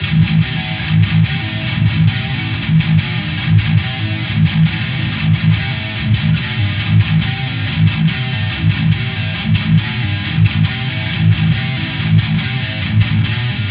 重型失真静音假吉他
这是一个假的重金属声音的吉他。
只需要用一些电平和失真来玩，听起来就很不错。
标签： 140 bpm Heavy Metal Loops Guitar Electric Loops 2.31 MB wav Key : F
声道立体声